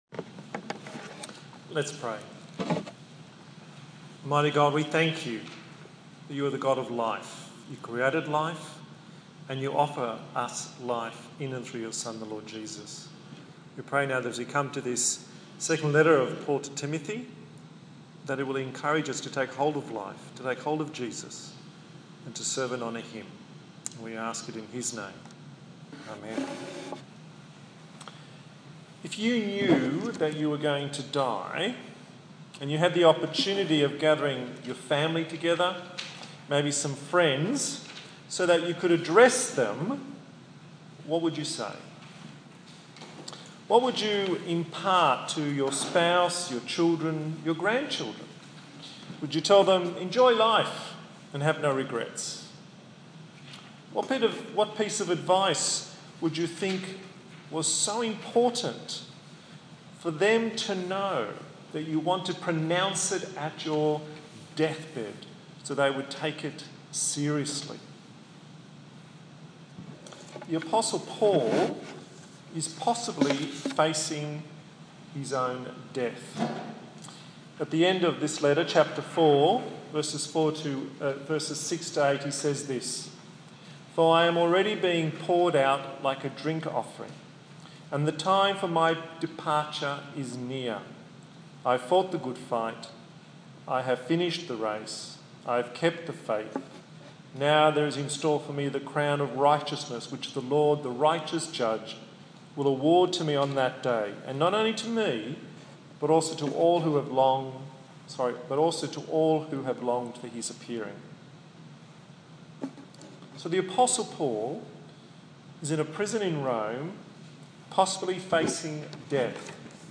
07/06/2015 The Promise of Life Preacher